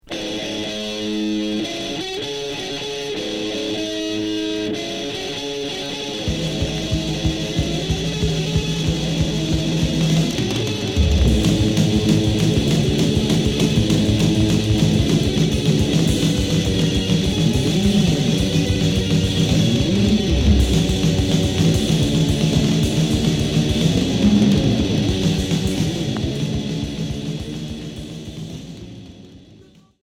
HARDCORE 2ème 45t